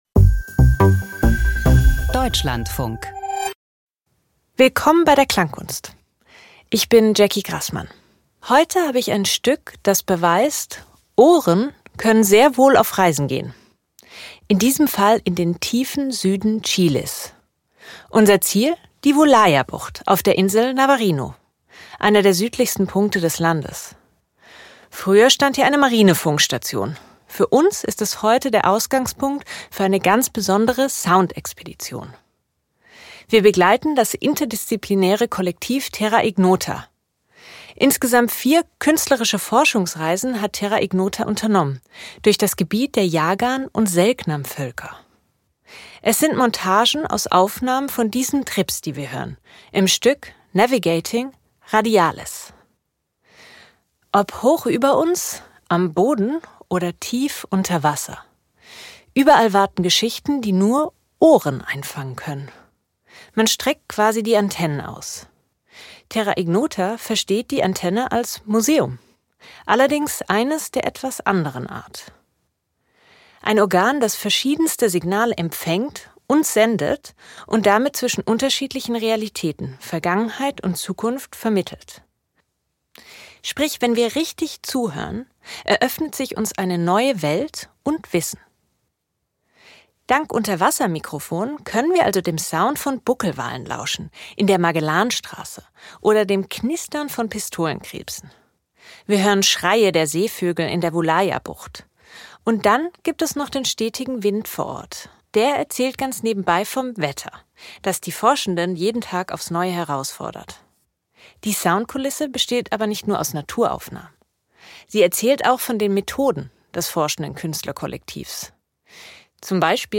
Doku-Hörspiel über Stasi-Dokumente - 50 Aktenkilometer
Spitzel und Bespitzelte lesen in den Überwachungsberichten der Stasi-Unterlagen-Behörde – eine irritierende Erfahrung.